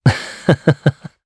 Esker-Vox_Happy2_jp_b.wav